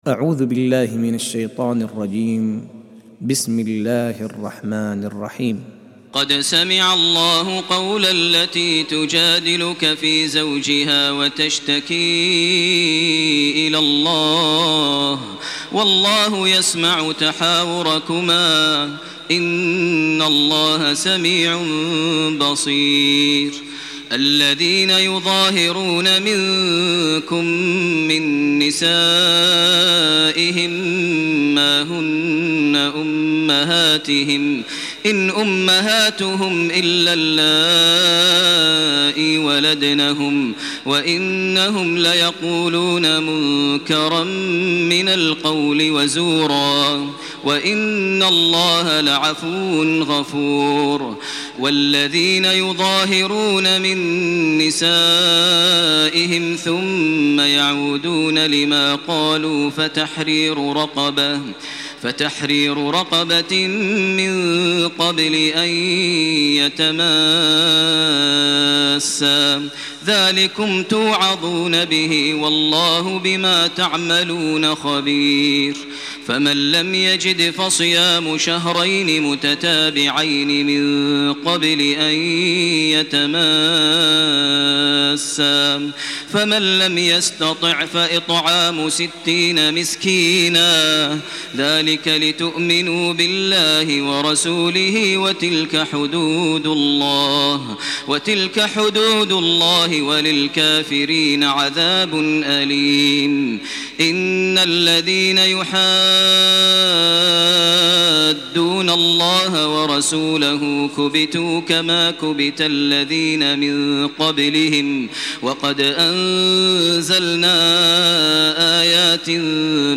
تراويح ليلة 27 رمضان 1428هـ من سورة المجادلة الى الصف Taraweeh 27 st night Ramadan 1428H from Surah Al-Mujaadila to As-Saff > تراويح الحرم المكي عام 1428 🕋 > التراويح - تلاوات الحرمين